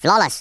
Worms speechbanks
flawless.wav